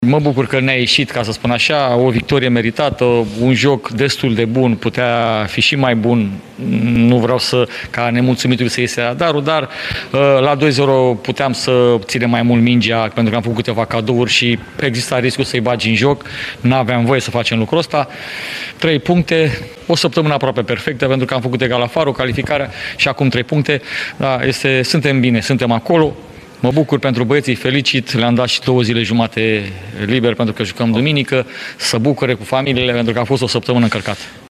De partea cealaltă, ”principalul” sibienilor, Marius Măldărășanu s-a declarat mulțumit de abordarea jocului de față, dar mai ales de ceea ce el a numit o săptămână aproape perfectă (cu două jocuri fără înfrângere în campionat și calificarea în finala Cupei României):
Maldarasanu-despre-o-saptamana-aproape-perfecta.mp3